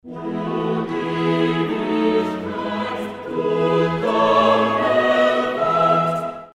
choral court-01 tres court.mp3